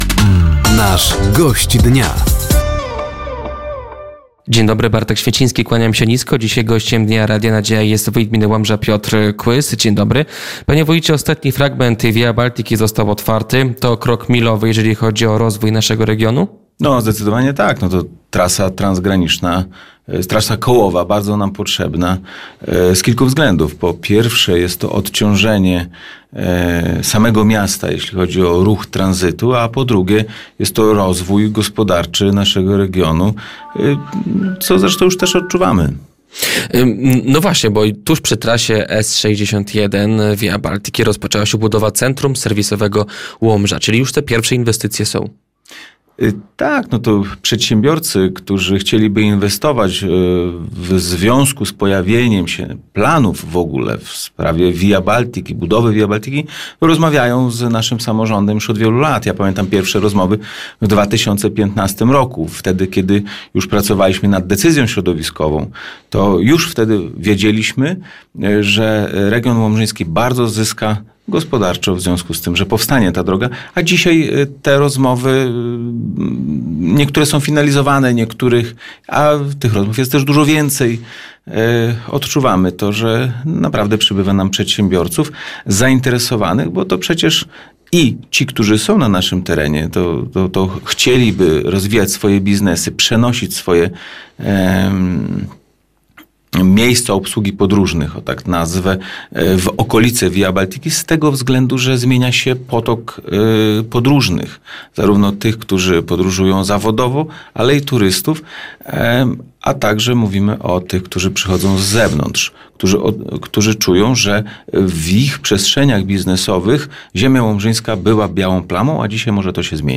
Gościem Dnia Radia Nadzieja był wójt gminy Łomża Piotr Kłys. Tematem rozmowy była Via Batlica i jej wpływ na rozwój regionu oraz CPK i interwencje posłanek w Łomży w tej sprawie.